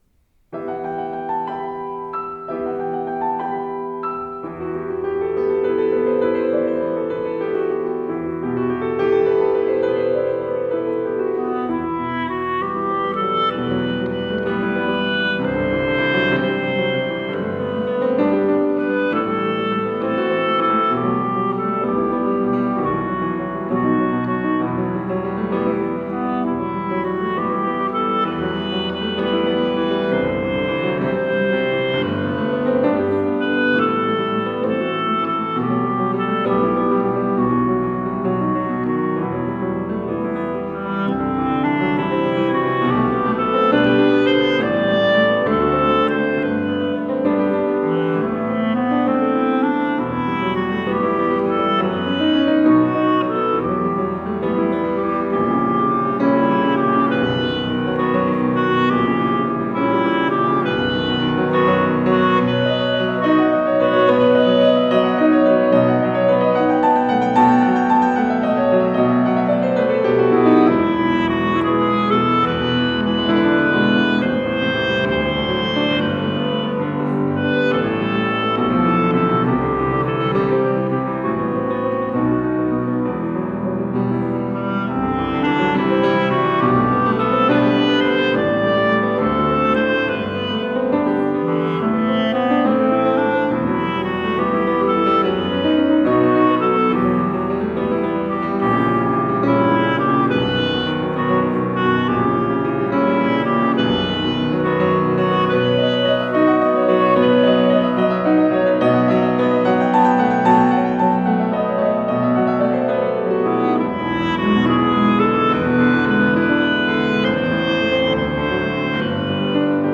Ils associent avec finesse les sonorités douces de la clarinette aux harmonies riches du piano.
Dans cet arrangement, c’est la clarinette qui énonce la magnifique mélodie principale composée par Smetana qui s’écoule au-dessus du tumulte de l’orchestre, interprété par le piano.